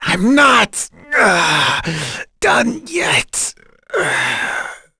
Dimael-Vox_Dead_b.wav